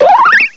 cry_not_chespin.aif